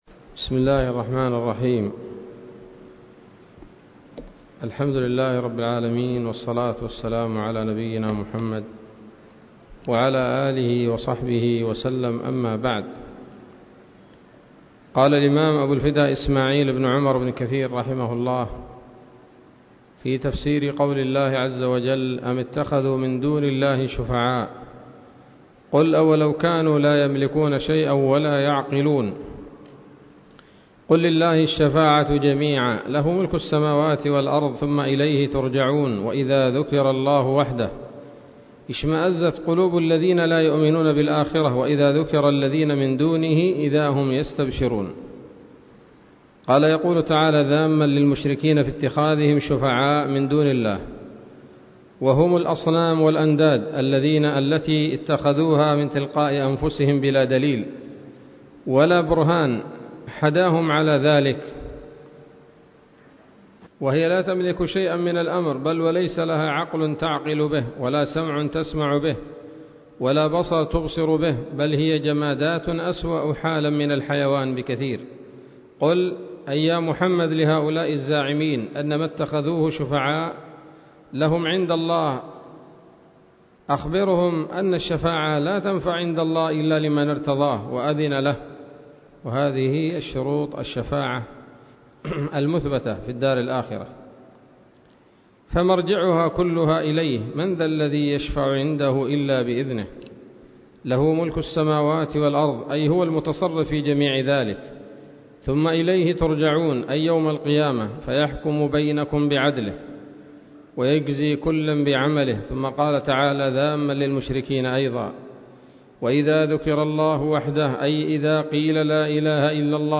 الدرس الثاني عشر من سورة الزمر من تفسير ابن كثير رحمه الله تعالى